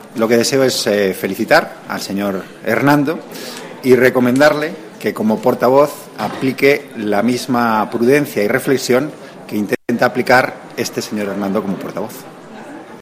Declaraciones de Antonio Hernando tras conocerse el nombre del nuevo portavoz del PP en el Congreso 16/12/2014